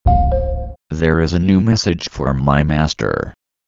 نغمة صوت الهليكوبتر
Sound Effects